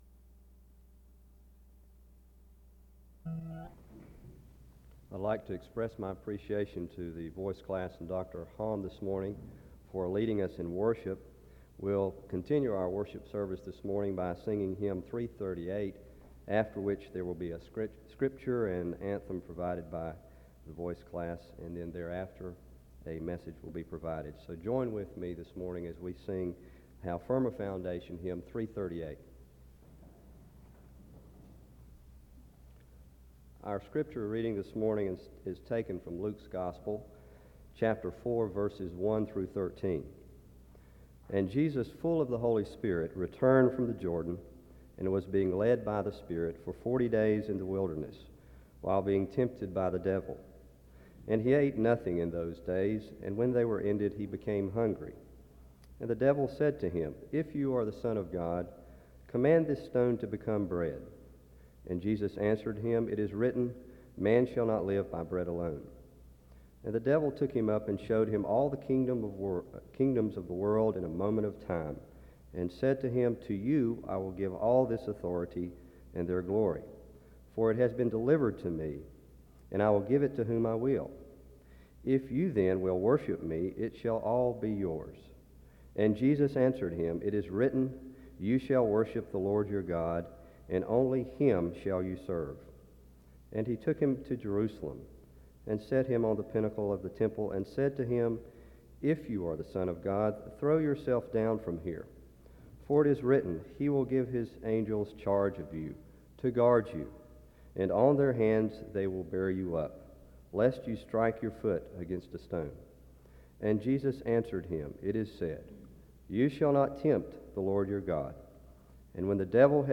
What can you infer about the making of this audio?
The audio was transferred from audio cassette.